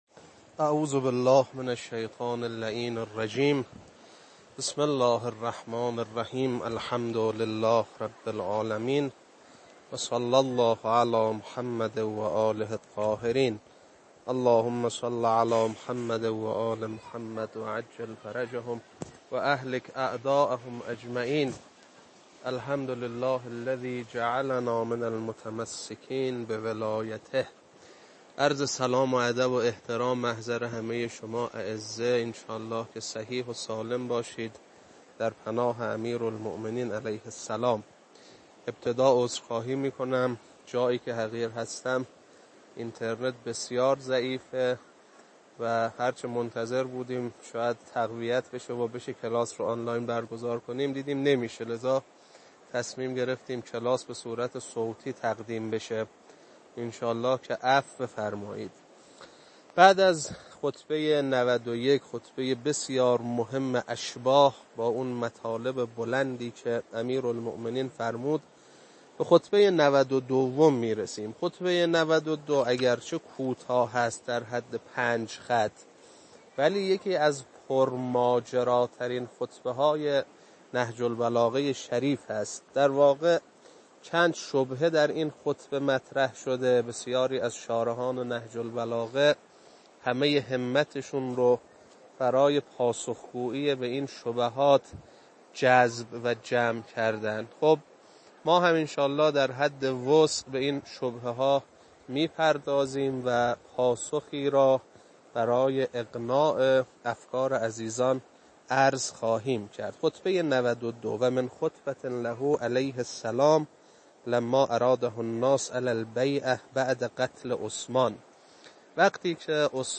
خطبه-92.mp3